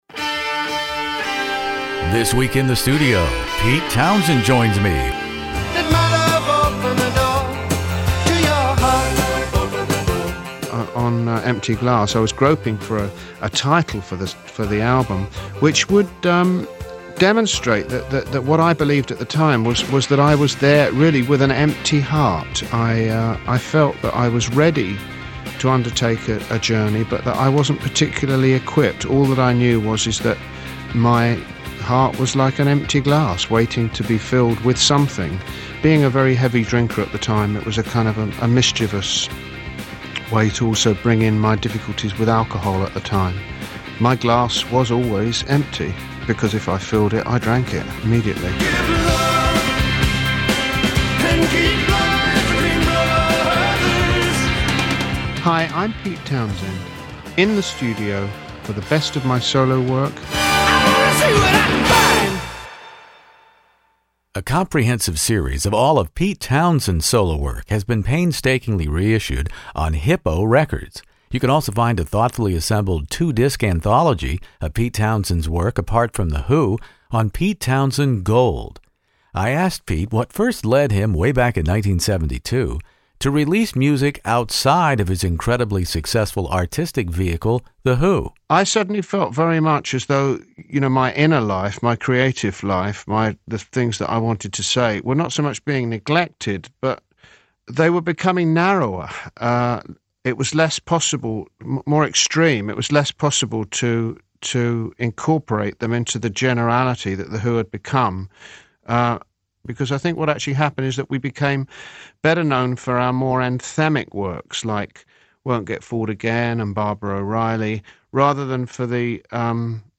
Pete Townshend Who Came First interview In the Studio